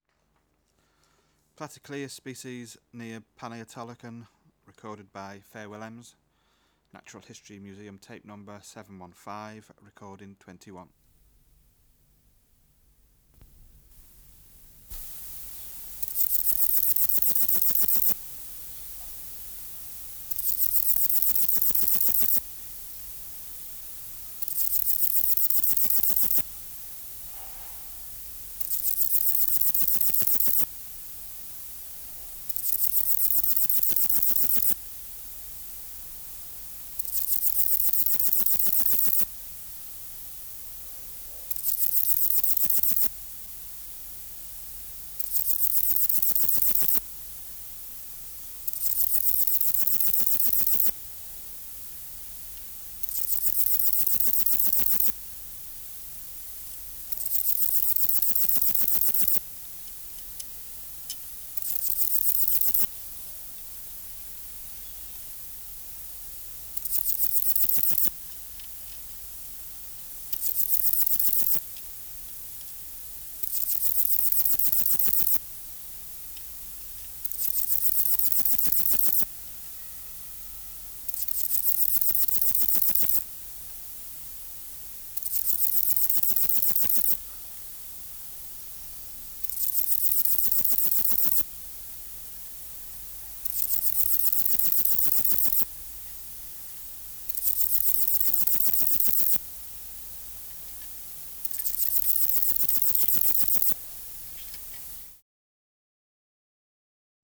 Project: Natural History Museum Sound Archive Species: Platycleis sp. near panaetolokon